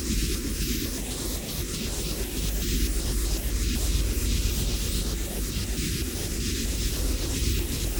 Index of /musicradar/stereo-toolkit-samples/Tempo Loops/120bpm
STK_MovingNoiseF-120_03.wav